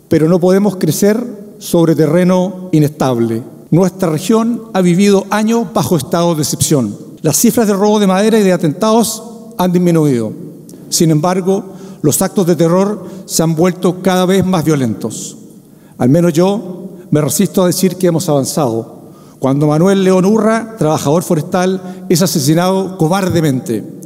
Lo anterior ocurrió en medio del Encuentro Regional de la Empresa (Erede), desarrollado en Concepción, región del Bío Bío, el cual contó con la presencia del Ministro de Economía y Energía, Álvaro García, y el titular de hacienda, Nicolás Grau.